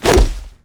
grenade throw.wav